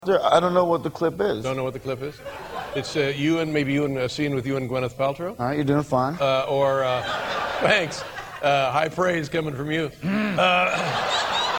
Tags: Joaquin Phoenix Joaquin Phoenix on Drugs Joaquin Phoenix on David Letterman Joaquin Phoenix interview funny clip